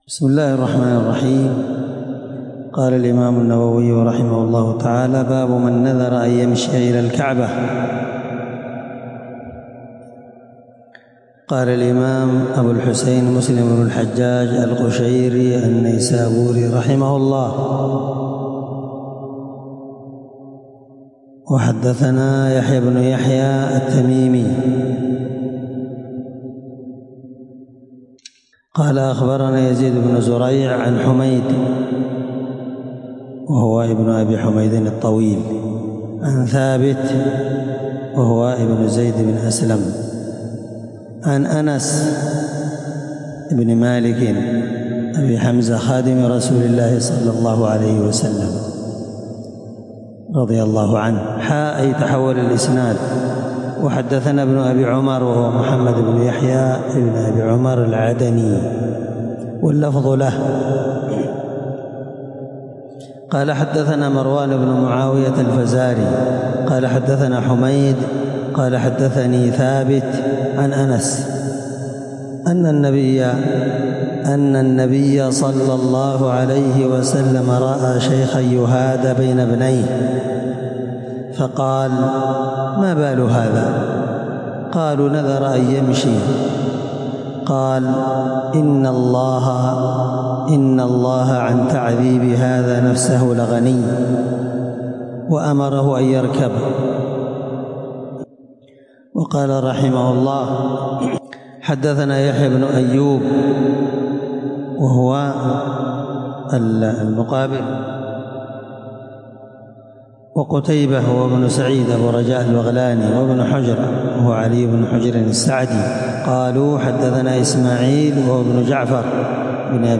الدرس4من شرح كتاب النذر حديث رقم(1642-1644) من صحيح مسلم